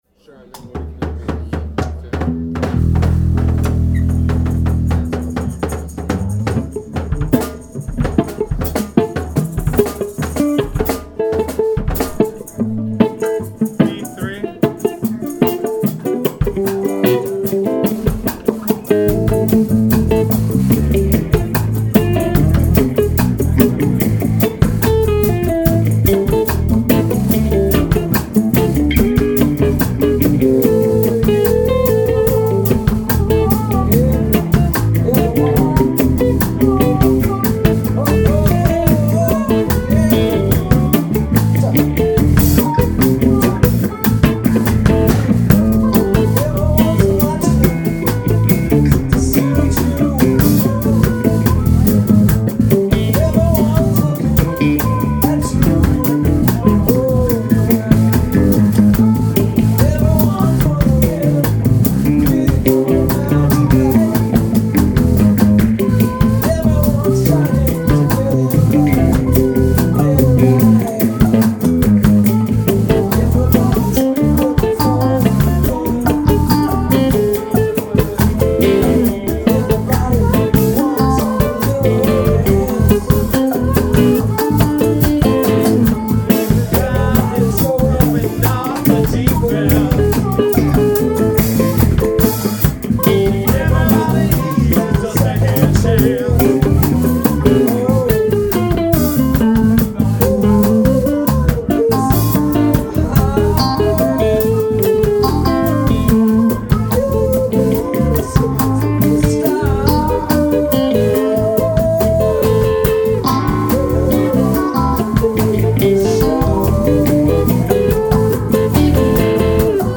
Set 1 (26 megs) Set 2 (20 megs) Set 3 (37 megs) Here are the recordings from WEGO's hosting of the open-mic at the Seamonster Lounge for the month of May (2013).
guitar and voice
trumpet, percussion, and voice … and bass for set 3
The usual teeming crowds of participants seemed noticeably thinned for this Memorial Day evening, so the band was able to fit in a bit more of its thing than usual.
Even so, with no drummer and me typically-out-of-practice on the percussion-pile, our first set was probably a little rougher than usual.